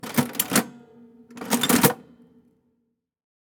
gear_01.wav